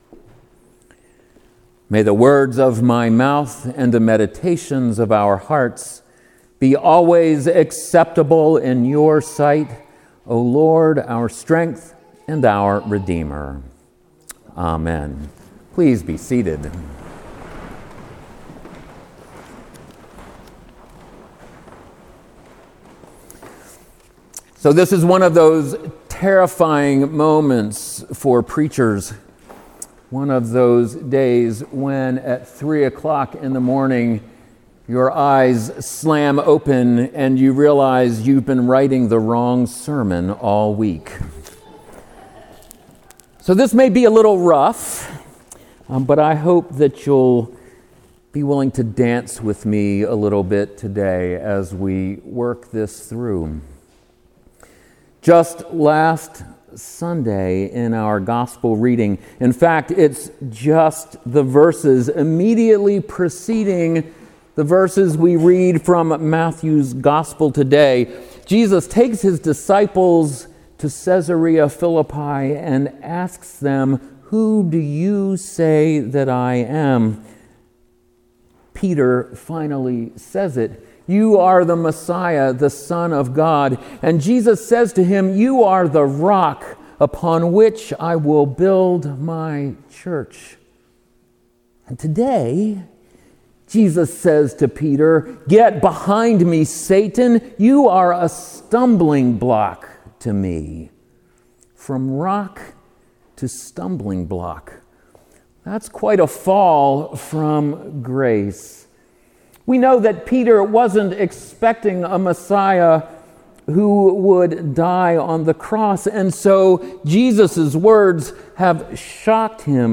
The following sermon, offered at Saint Andrew’s Episcopal Church in Madison Wisconsin, on September 3, 2017, is built around the readings for Proper 17 in year A of the Revised Common Lectionary.
This sermon was preached from the center aisle without notes.